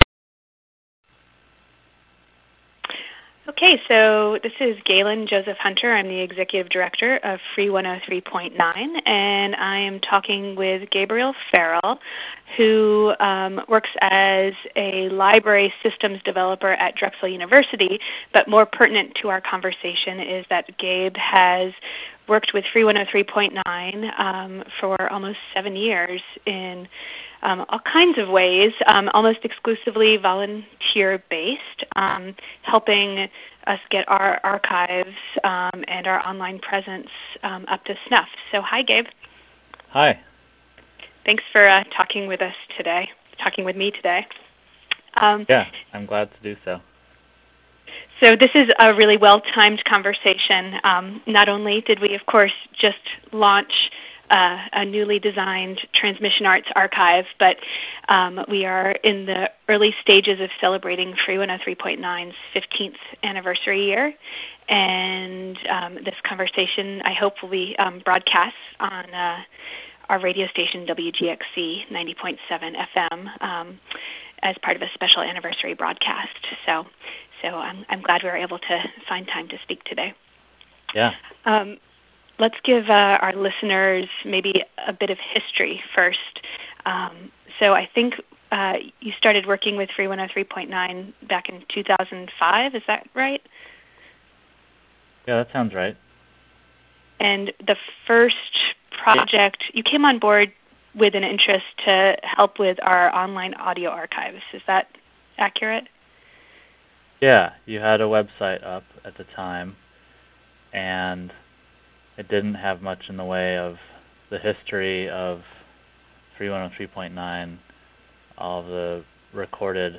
free103point9 interview